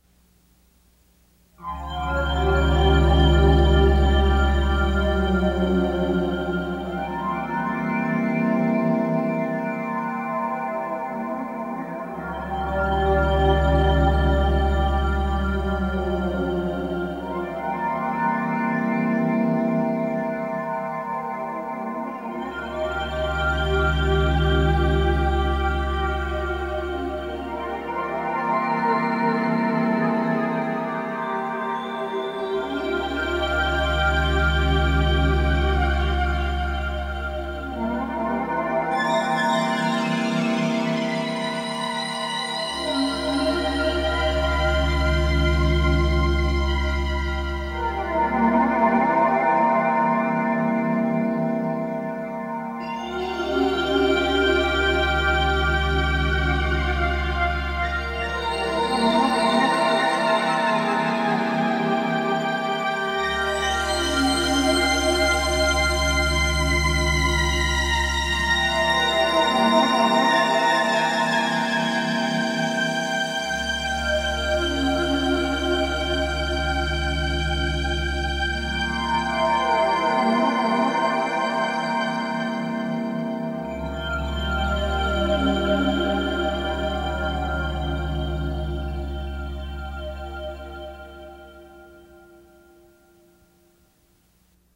A modestly ambitious (at least, for this amateur musician and would-be recording studio engineer) 4-track home recording of some pieces quite on my mind at the time. The master tape had deteriorated somewhat over the years prior to its eventual transfer to digital media in the mid-2000s.